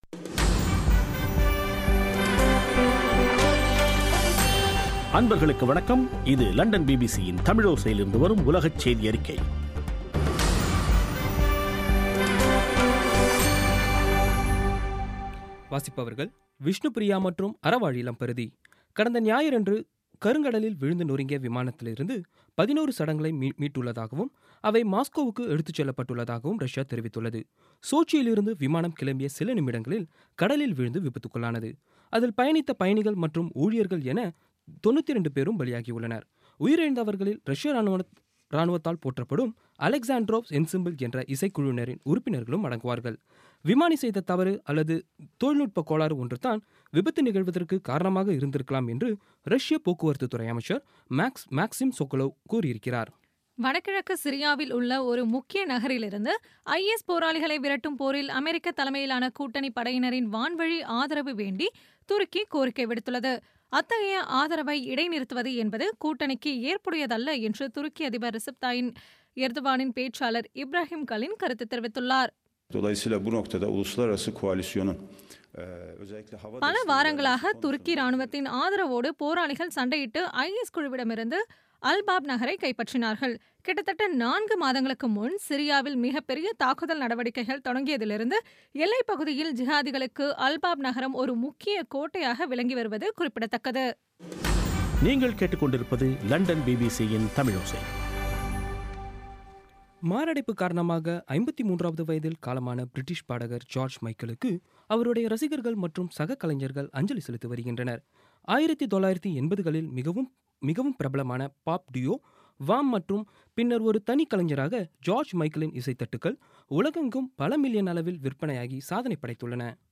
பி பி சி தமிழோசை செய்தியறிக்கை (26/12/16)